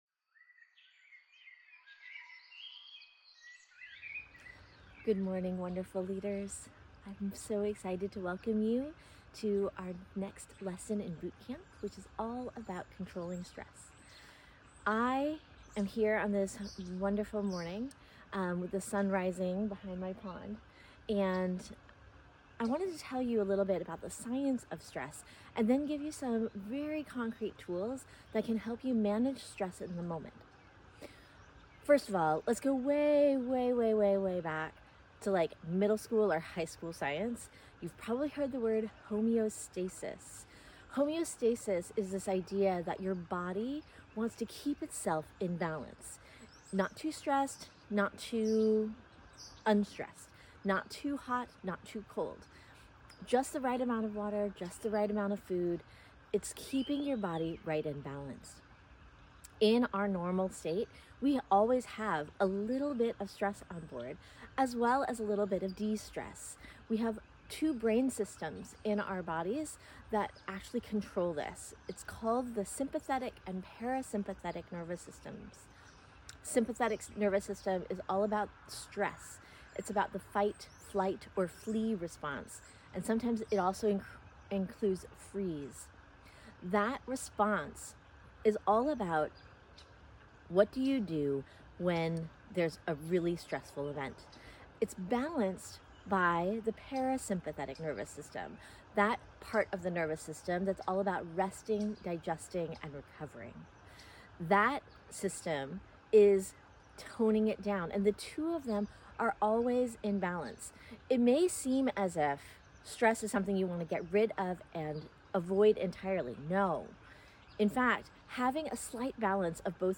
I lead you through several of my favorite brain breaks – 20 second to 2 minute interventions that resolve the stress response and return you to a state of healthy balance.